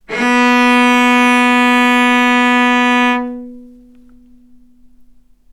vc-B3-ff.AIF